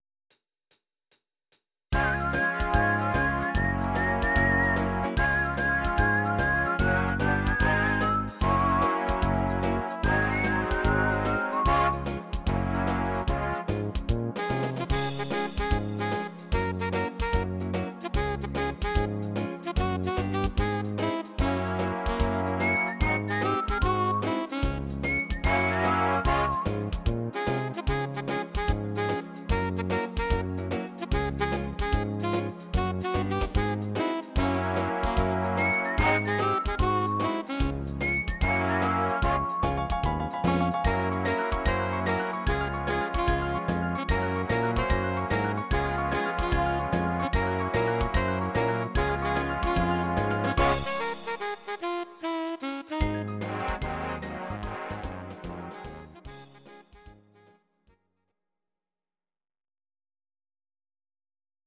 Audio Recordings based on Midi-files
Oldies, German, 1950s